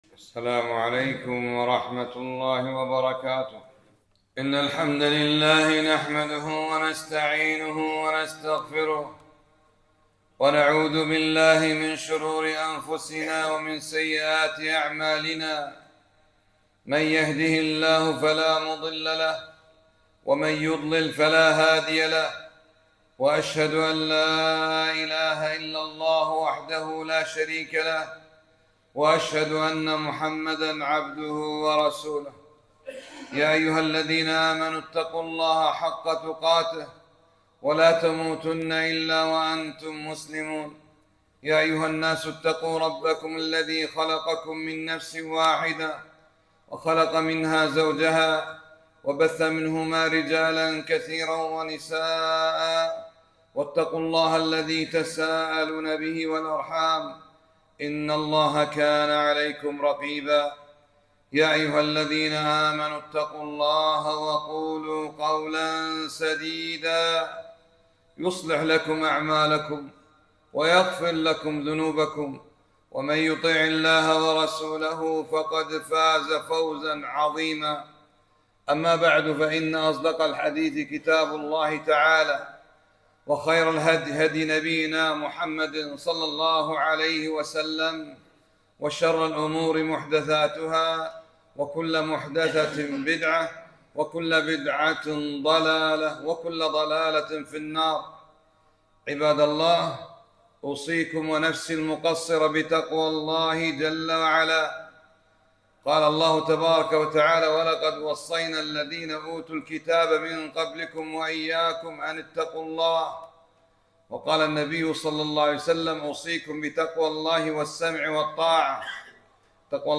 خطبة - أهوال يوم القيامة